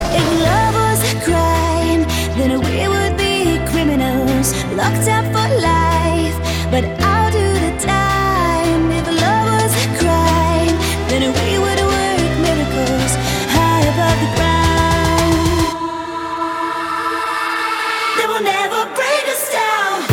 поп
женский вокал